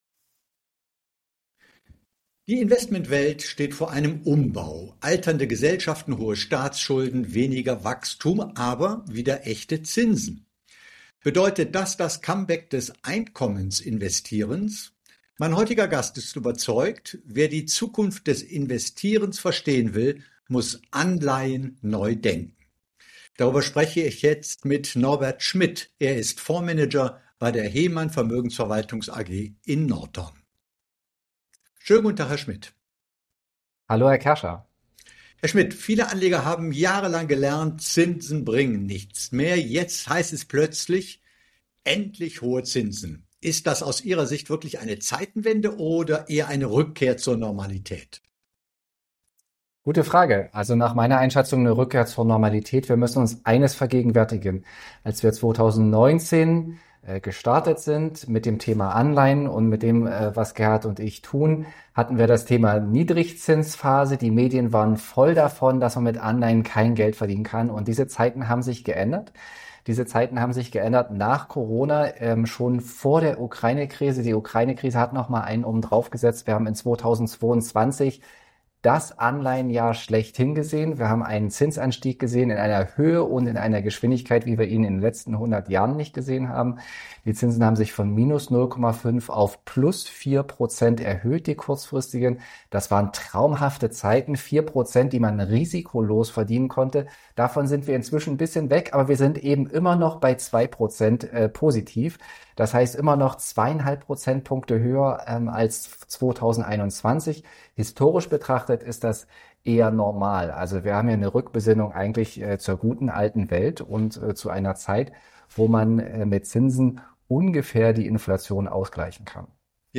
Ein Gespräch für alle, die ihr Portfolio breiter aufstellen wollen – und Einkommen nicht dem Zufall überlassen möchten.